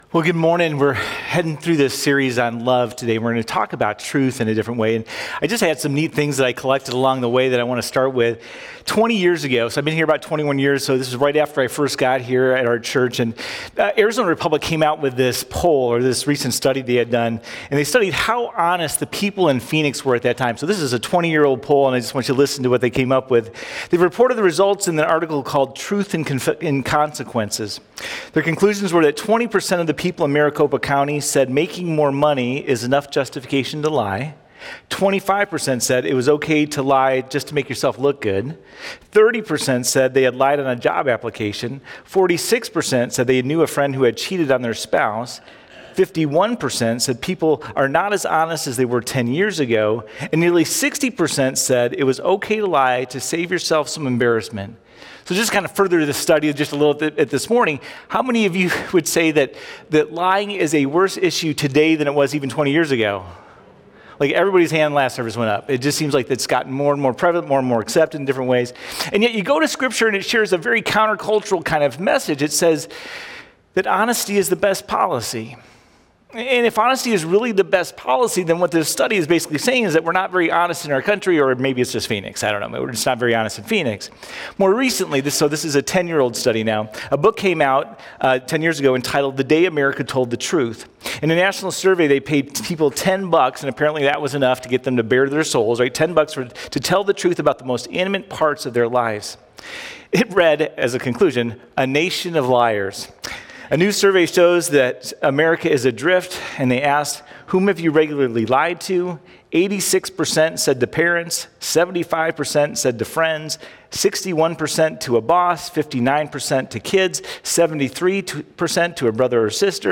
04-06-Sermon.mp3